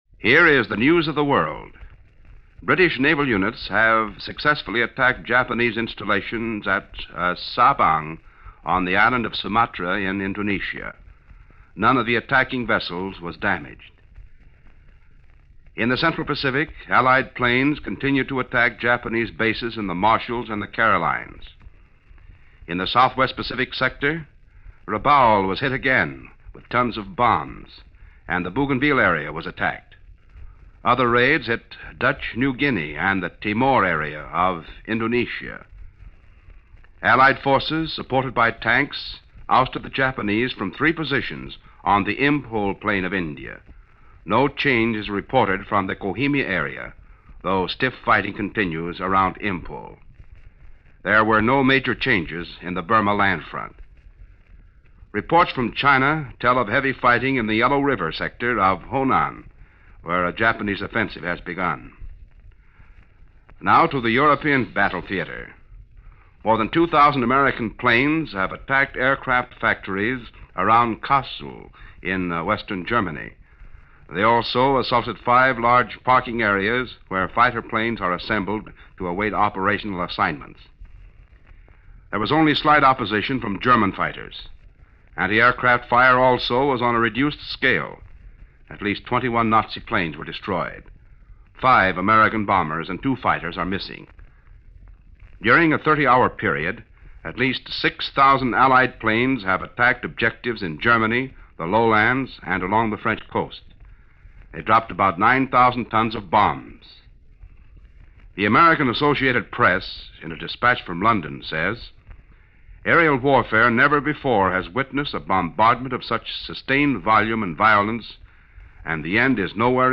Office Of War Information News broadcast